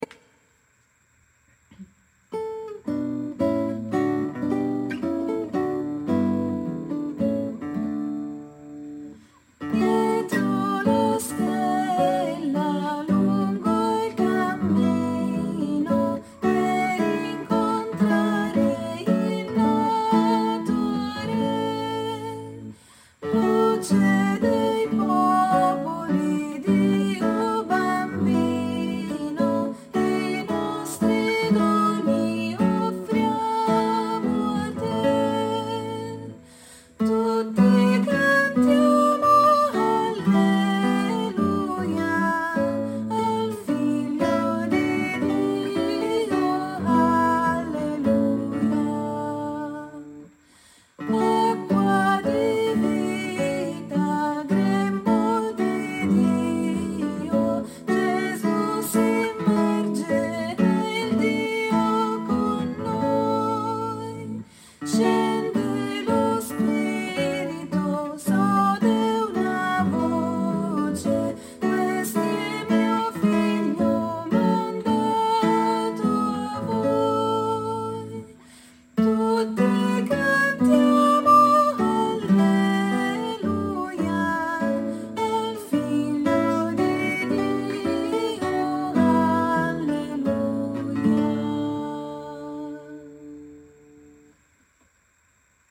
versione chitarra
Cantano-gli-angeli_chitarra.mp3